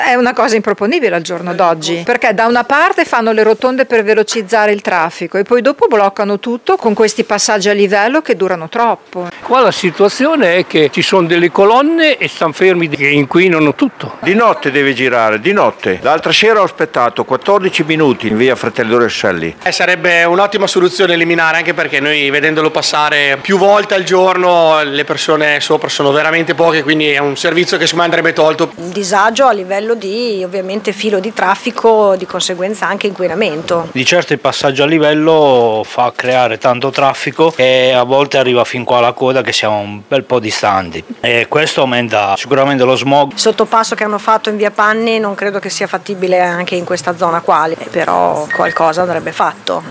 Un tema dibattuto da anni quello del traffico paralizzato ai passaggi a livello in città, le interviste qui sotto: